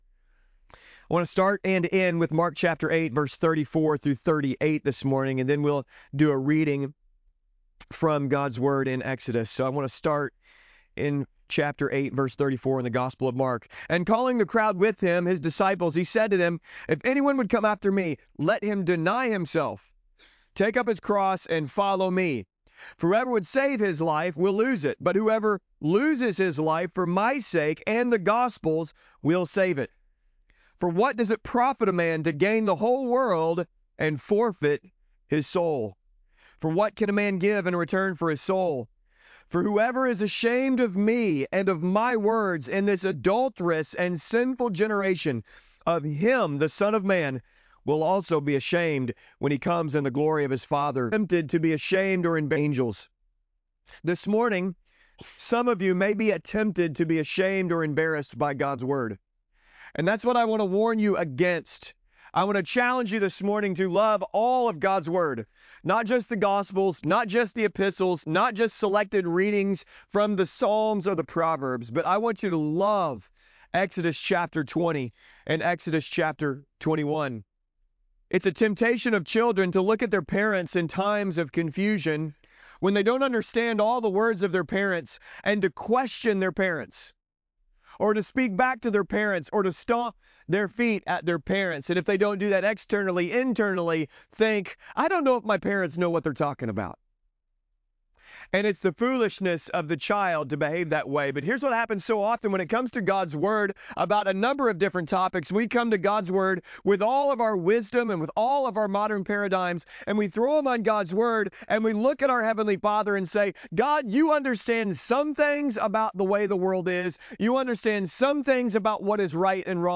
These are sermons from our church.
Preaching from our Lord's Day Gathering